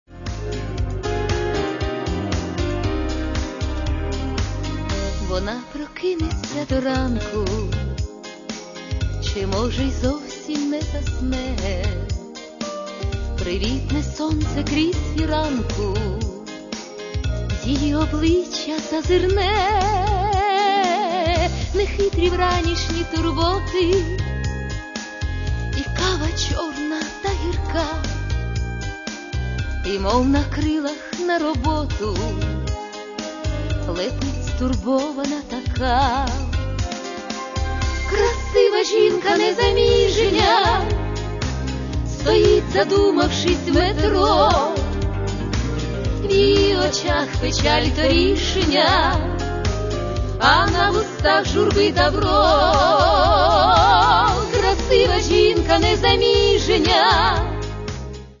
Каталог -> Эстрада -> Певицы